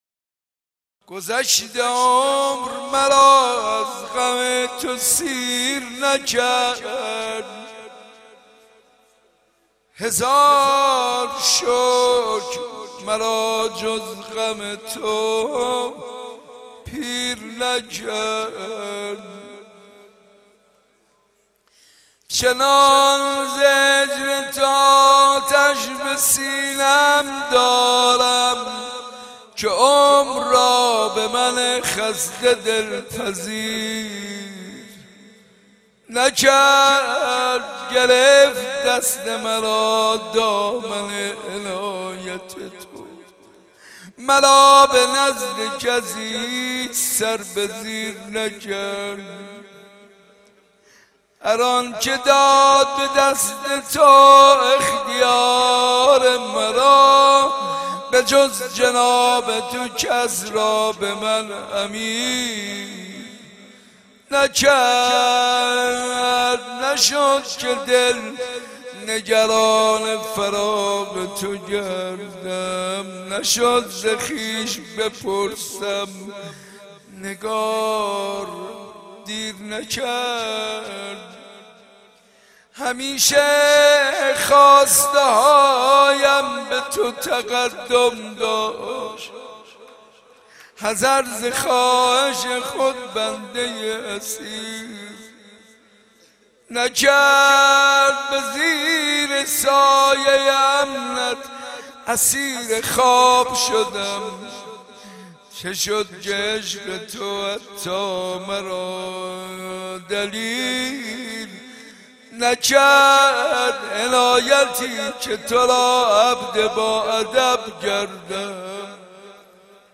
حاج منصور ارضی در جلسه هفتگی زیارت عاشورا
به گزارش عقیق حاج منصور ارضی مداحی خود را با این شعر آغاز کرد